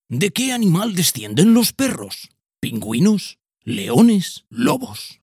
TEST HISTORIA PERRO-Narrador-06_0.mp3